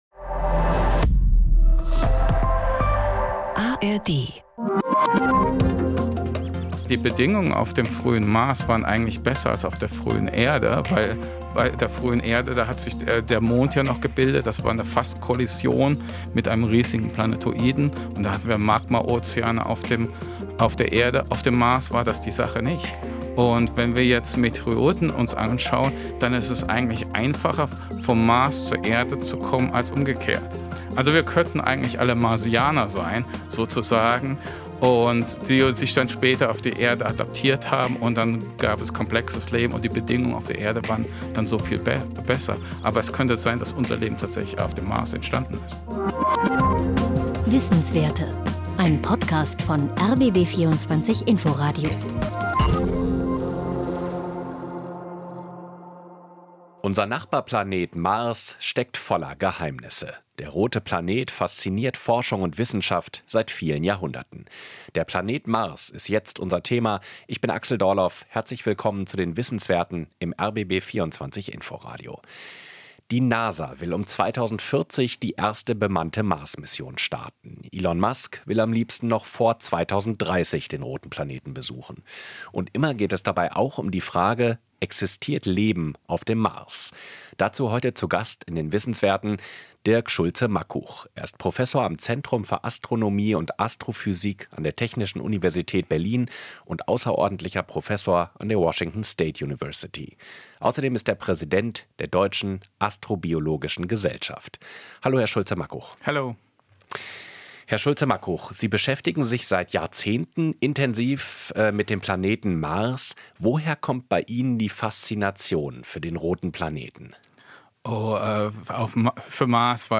Unser Universum - das war das Motto des Wissenschaftsjahres 2023.